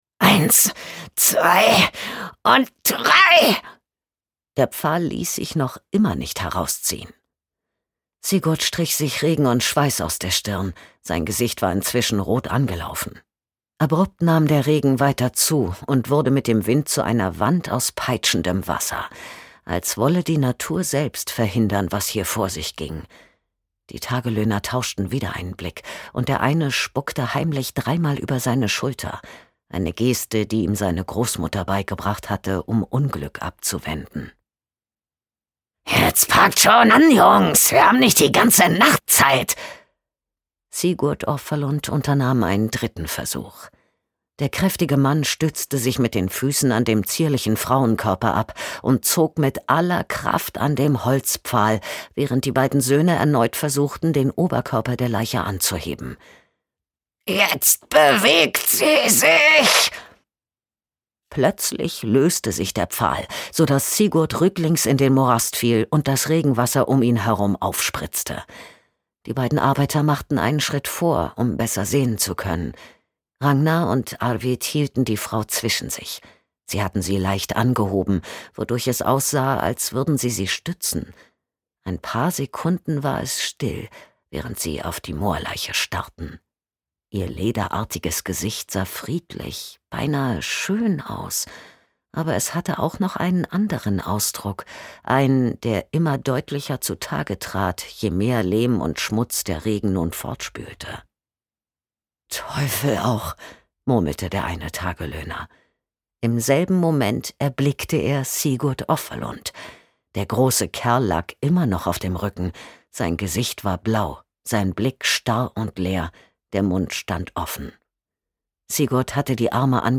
Rostiges Grab - Anders de la Motte | argon hörbuch